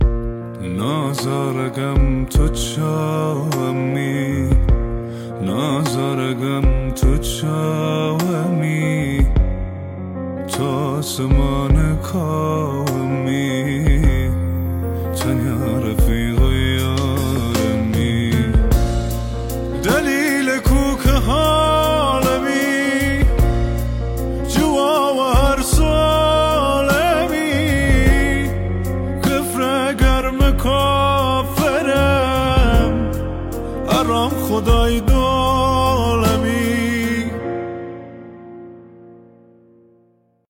ترانه کردی سوزناک و پرطرفدار